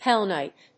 アクセント・音節pén・lìght